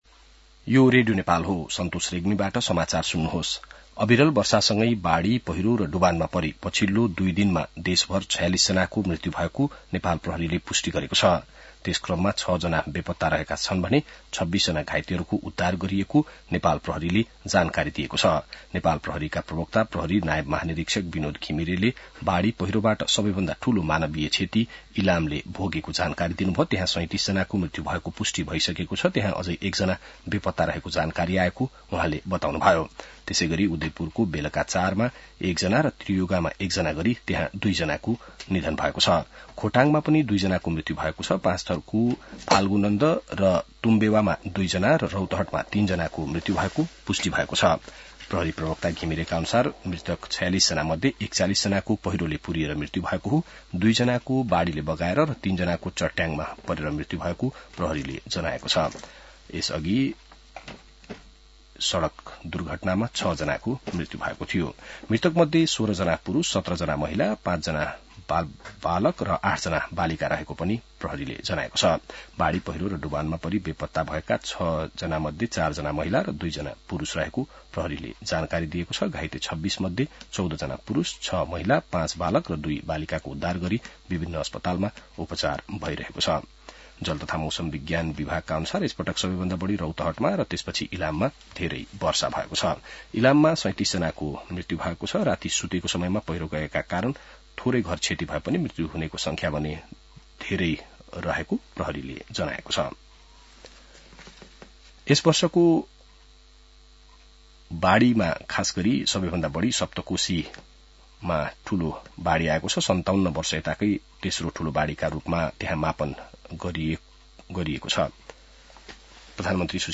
बिहान ६ बजेको नेपाली समाचार : २७ जेठ , २०८२